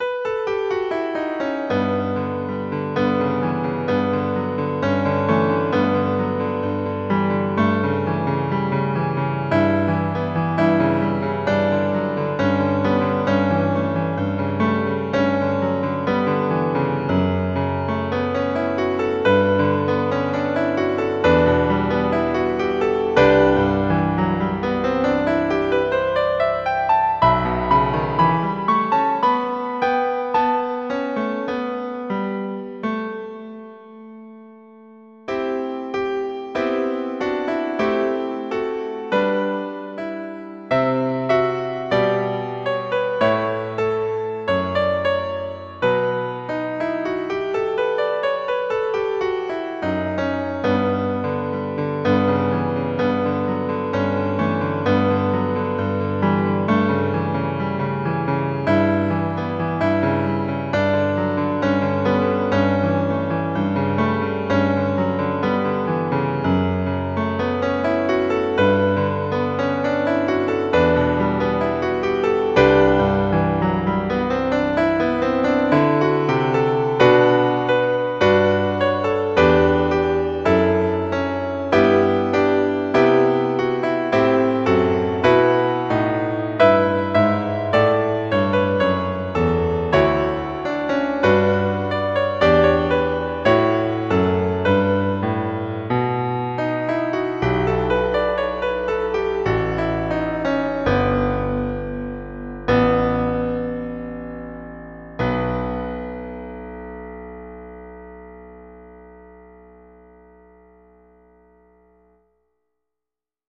Antologia di 18 brani per Organo o Pianoforte